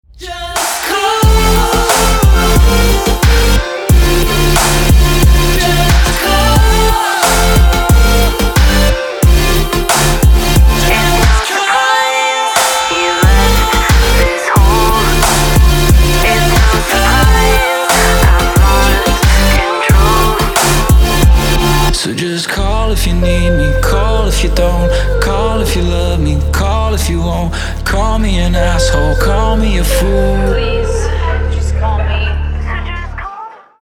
• Качество: 256, Stereo
мужской вокал
dance
Electronic
EDM
club
Future Pop